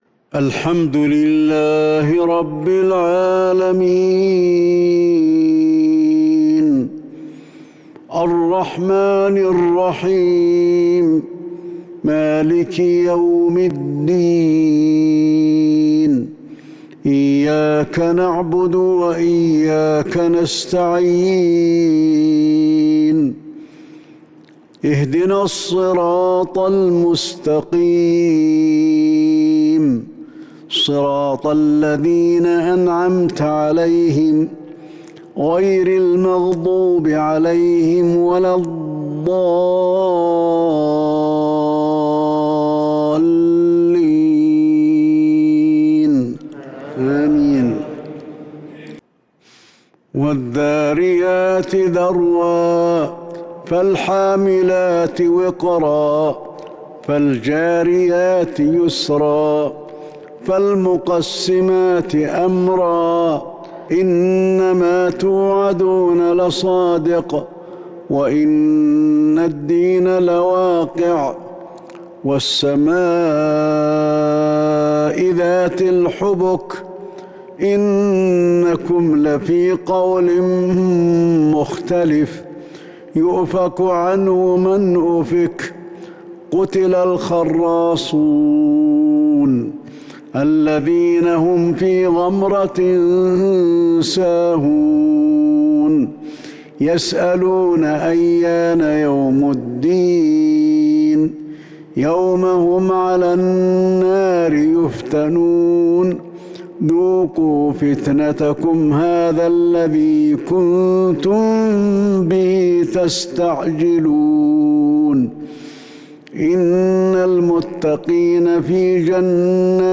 صلاة العشاء من سورة الذاريات 9-3-1442 Isha prayer from Surat Al-Dhariyat 10/26/2020 > 1442 🕌 > الفروض - تلاوات الحرمين